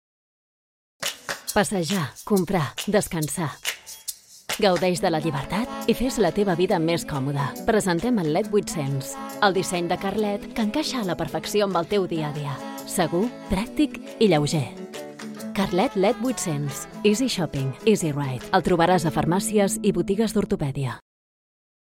Female
Assured, Bright, Character, Children, Confident, Cool, Corporate, Friendly, Natural, Smooth, Soft, Streetwise, Warm, Witty, Versatile, Young
Spanish (Spain; Iberian, Castillian)
Microphone: Newmann U87 Au
Audio equipment: Interface: Babyface RME